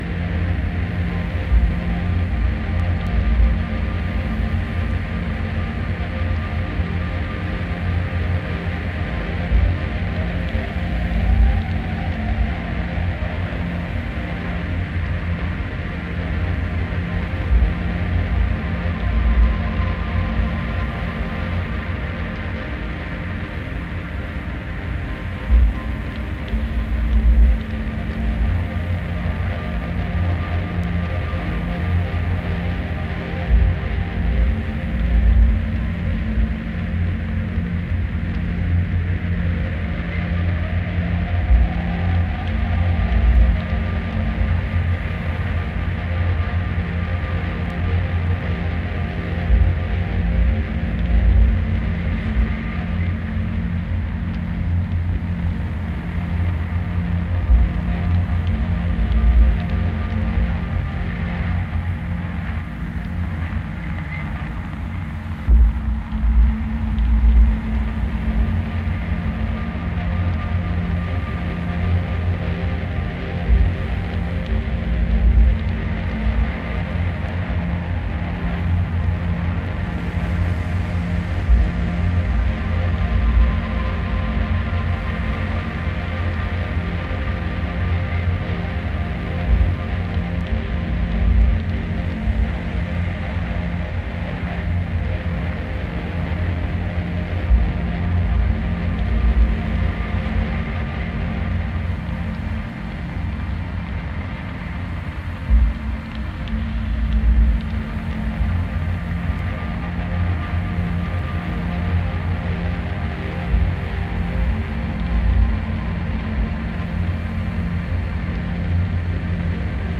Electronix Ambient LP